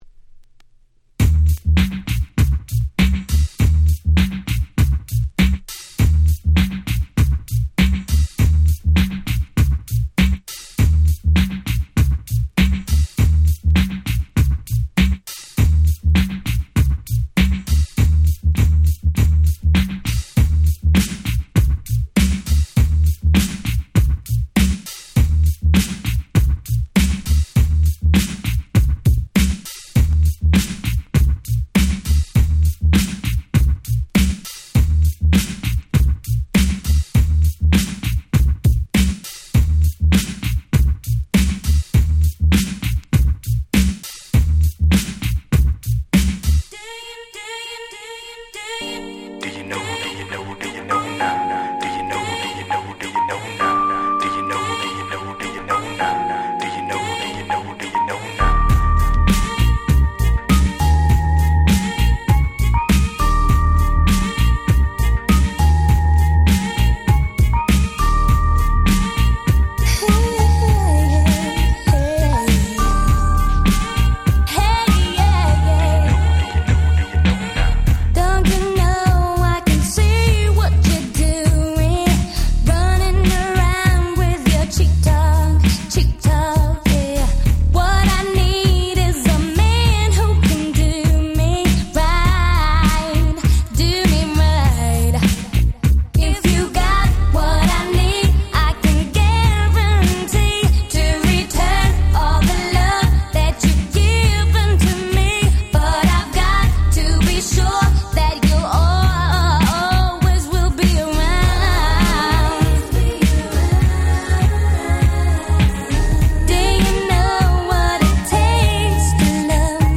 97' Super Hit R&B !!
スウェーデンの女性シンガー。
程良くキャッチーで最高！！